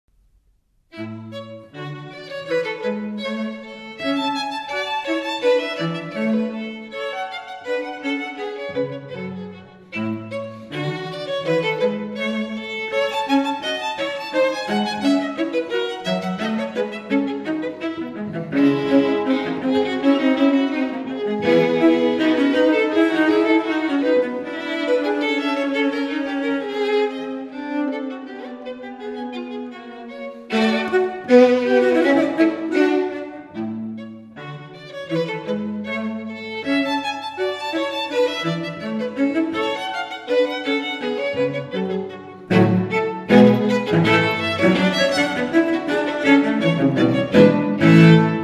For a cocktail or a ceremony, the cello and the violin – two voices in perfect harmony – will enchant you with their repertoire of classical music (with classics such as Vivaldi’s Four Seasons) but also jazz standards (for instance Michael Bublé’s Everything) and pop music (All my loving by the Beatles).
Harmonie parfaite de deux voix complémentaires, ce duo violon violoncelle vous propose son répertoire de musique de cérémonie et de cocktail composé de musique classique comme des extraits des Quatre Saisons d’Antonio Vivaldi mais aussi des standards de jazz comme Everything de Michael Bublé et de la musique pop comme All my loving des Beatles.
01-duo-violon-violoncelle-sonate-de-Glière-range.mp3